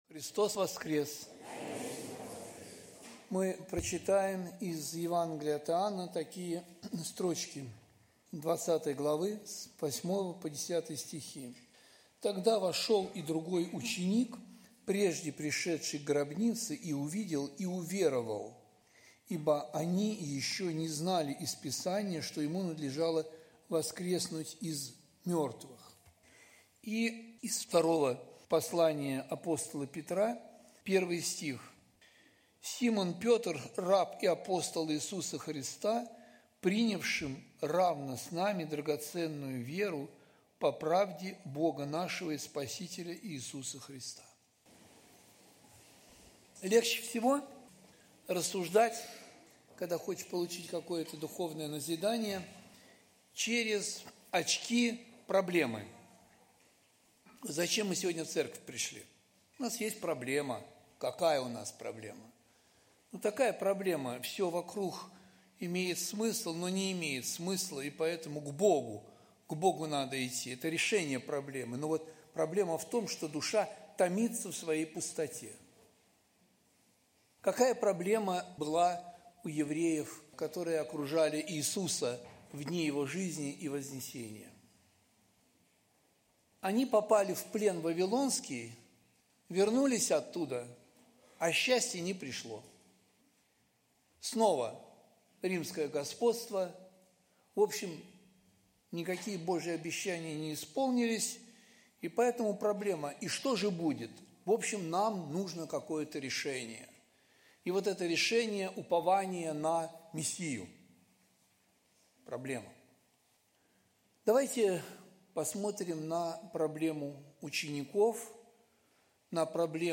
Проповеди
Сайт Московской Центральной церкви Евангельских христиан-баптистов.